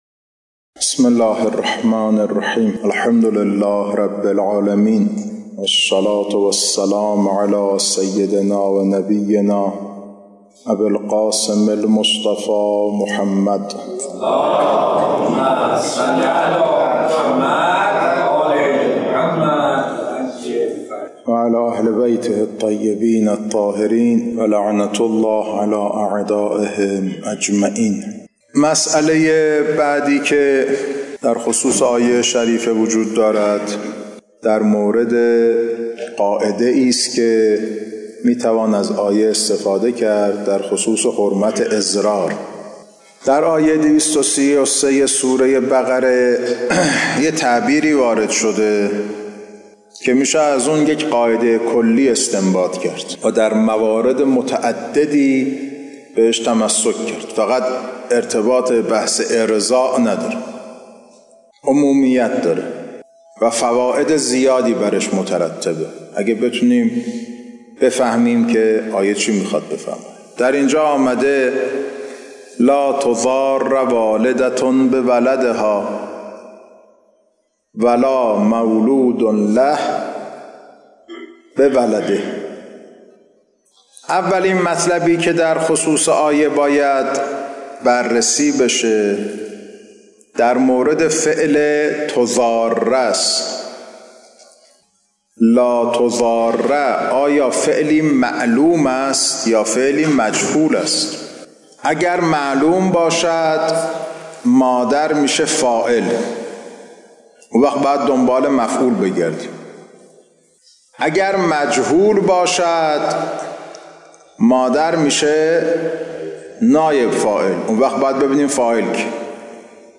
خارج فقه